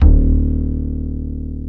EPM AKUSTIK.wav